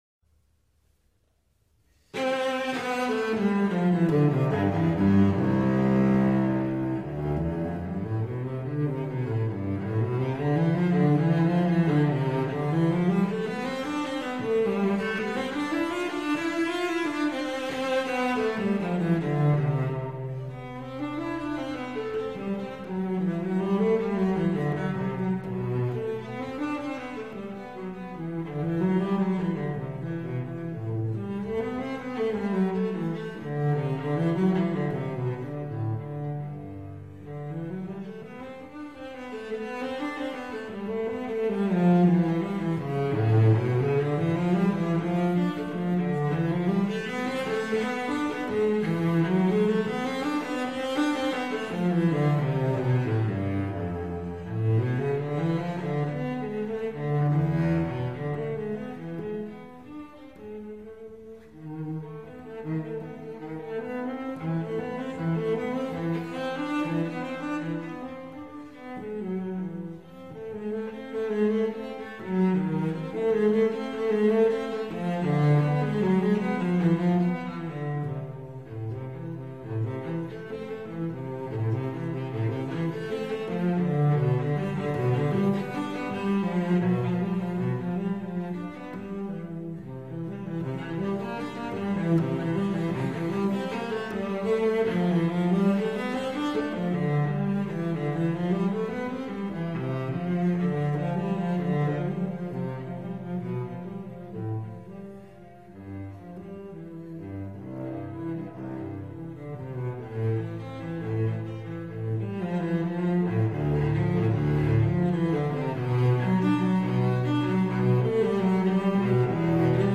Chello Suite No. 3 - Prelude (performed by Mischa Maisky)
Cello_Suite_No.3_-_Prelude.mp3